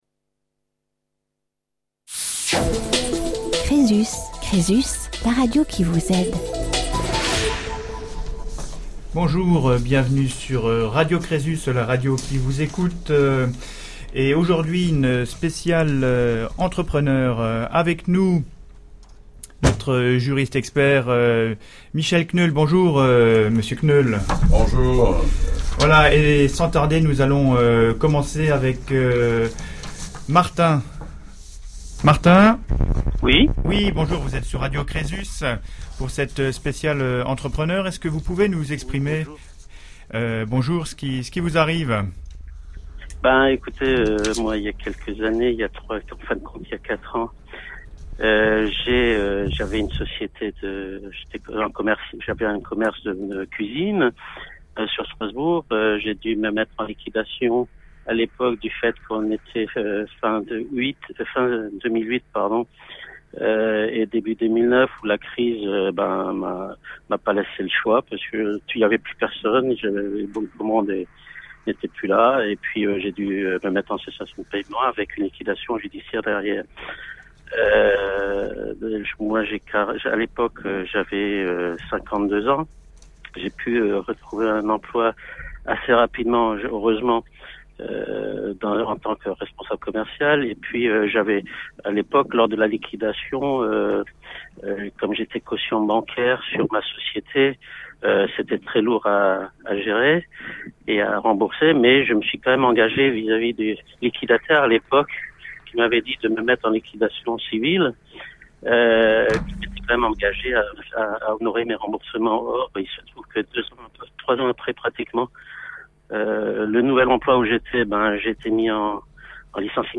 Emission spéciale « entrepreneurs » !
Nos entrepreneurs témoignent de leur condition, de leur engagement sans limites, et comment ils assument seuls le poids du risque de leur entreprise : des témoignages riches d’enseignements et d’humanité.